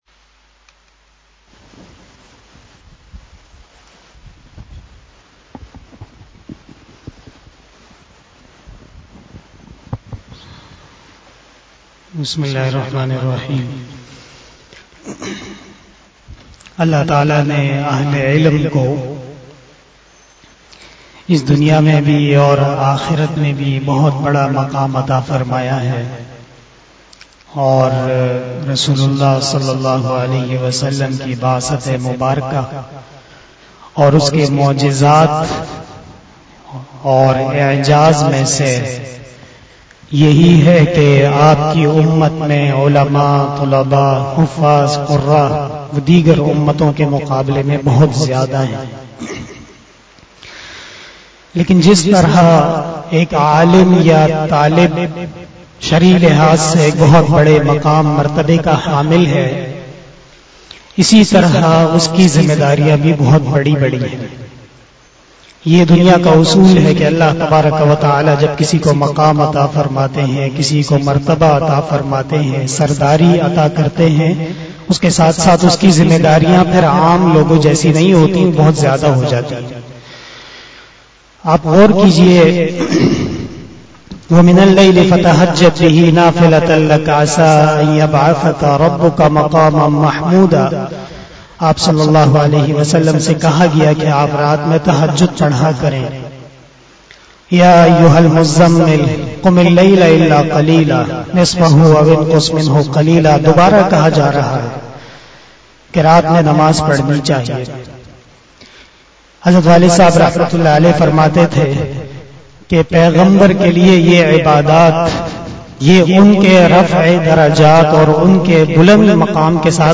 066 After fjar Namaz Bayan 04 October 2021 (26 Safar 1443HJ) Monday
بیان بعد نماز فجر